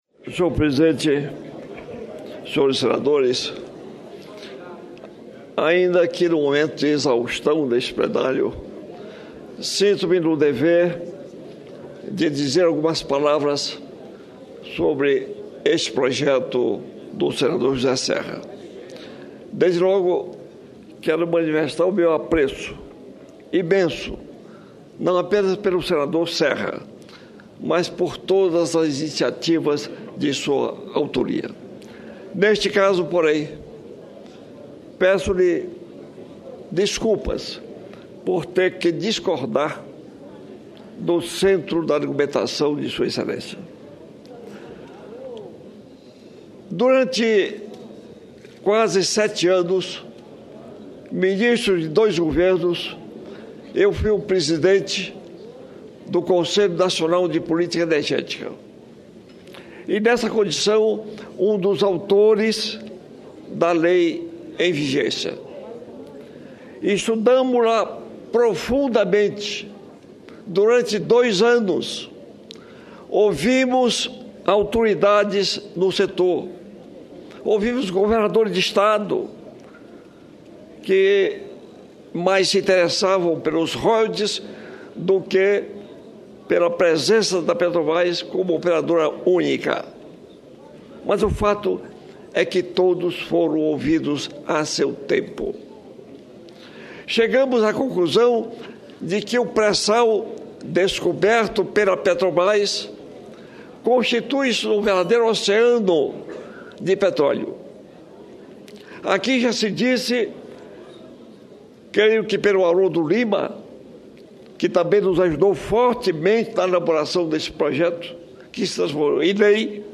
Pronunciamento do senador Edison Lobão